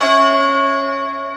Key-bell_92.1.1.wav